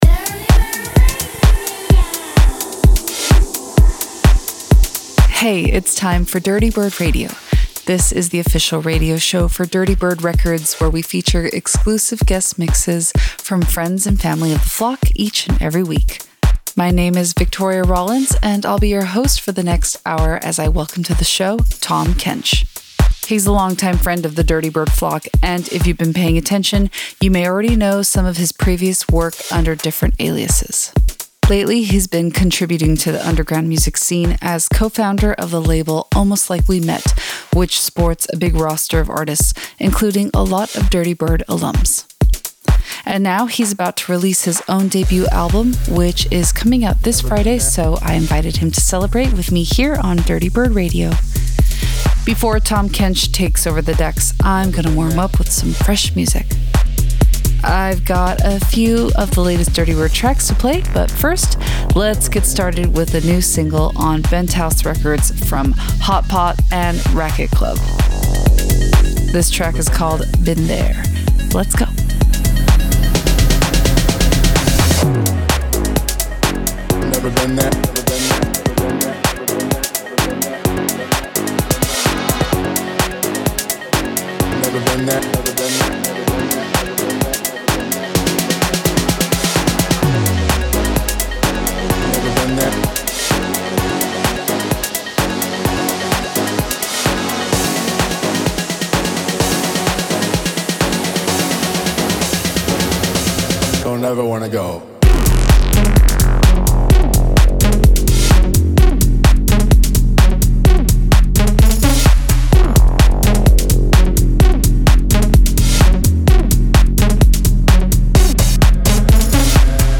house music experimentalism